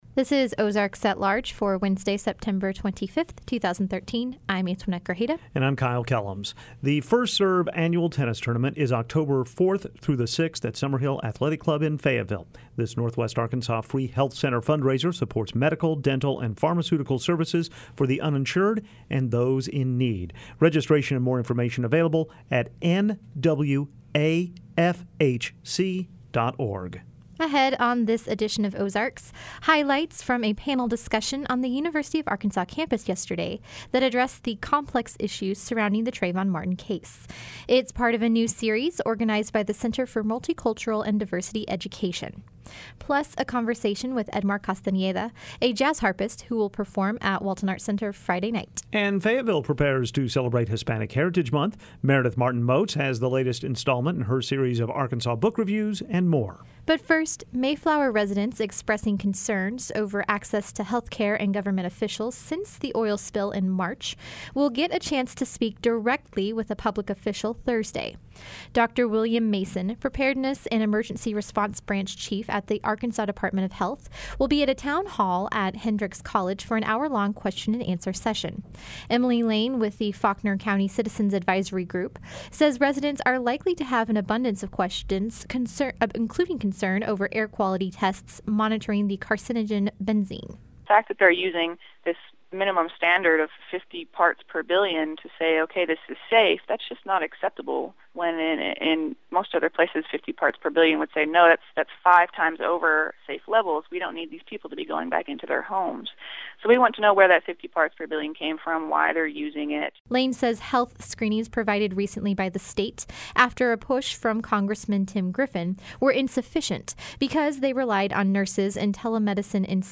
Ahead on Ozarks, highlights from a panel discussion addressing the complex issues surrounding the Trayvon Martin case. Plus, a conversation with jazz harpist Edmar Castañeda.